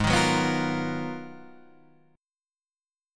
Abm13 chord